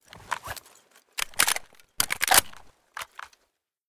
sa58_reload_speed.ogg